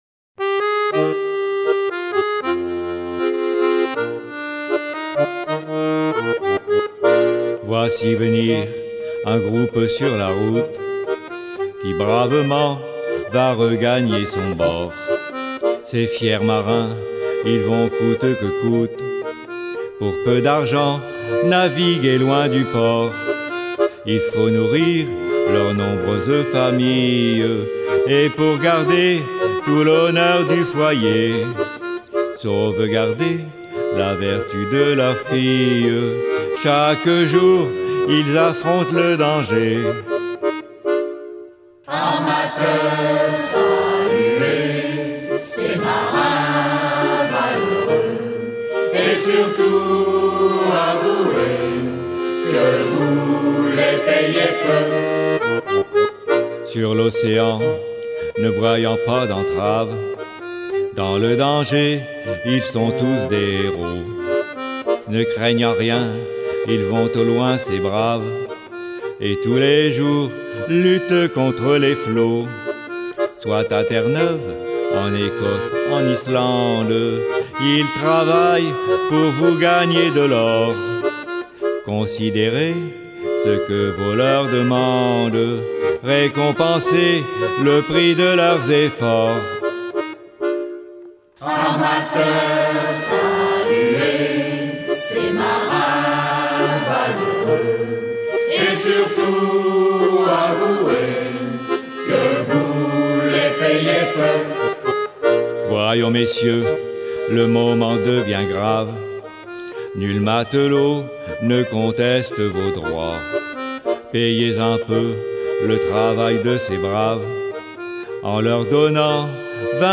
Genre strophique
Pièce musicale éditée